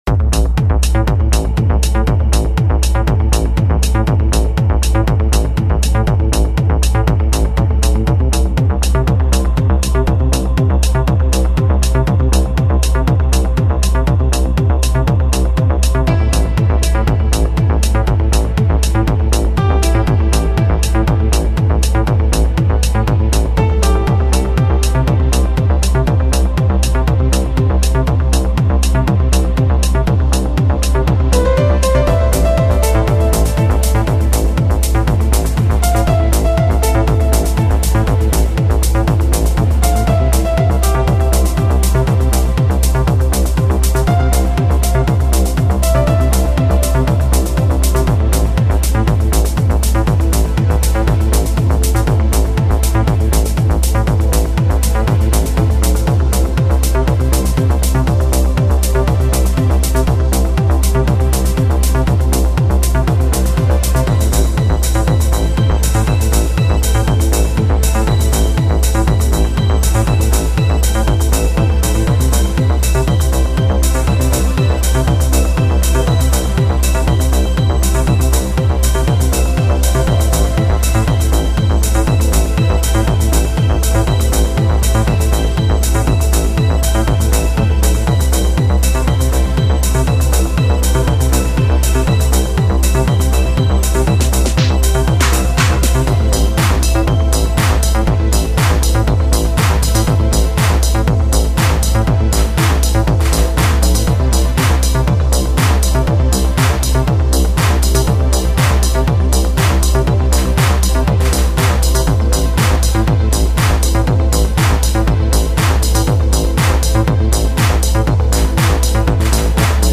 Intrumental Demo track